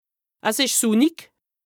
Français Dialectes du Bas-Rhin Dialectes du Haut-Rhin Page